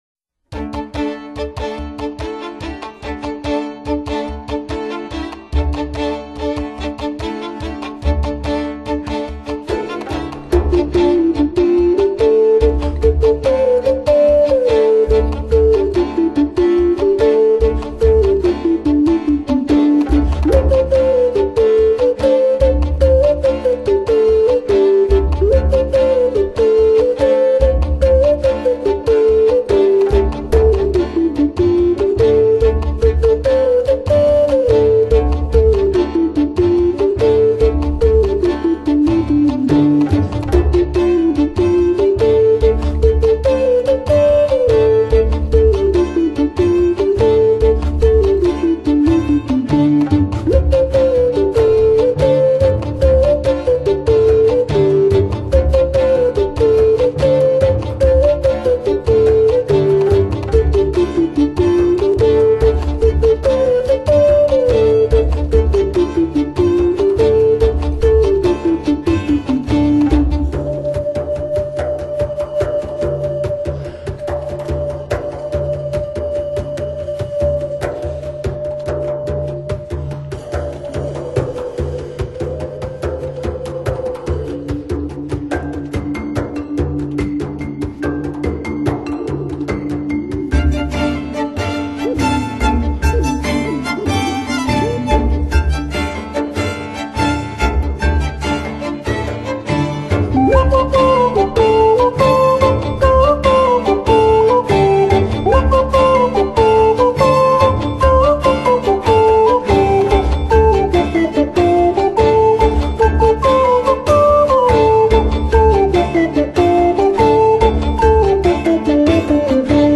陶笛挑戰愛爾蘭踢踏舞曲風